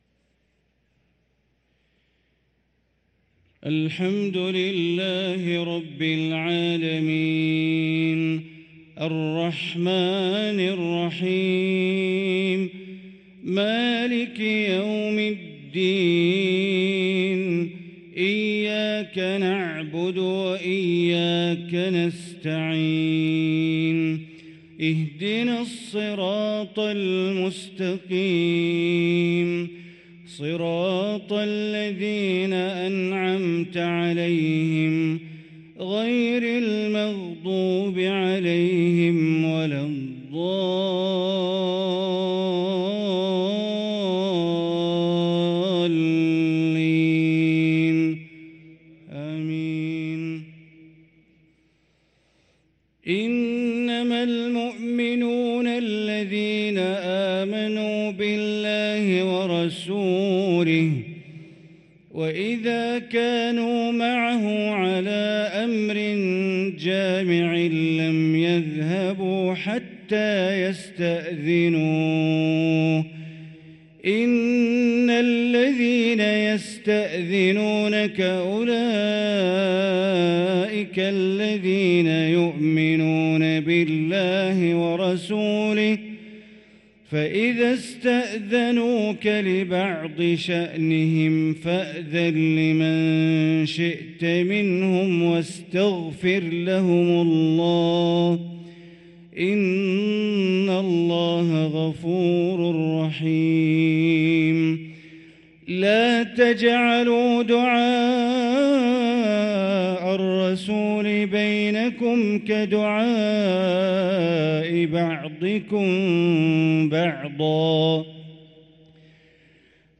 صلاة العشاء للقارئ بندر بليلة 6 شوال 1444 هـ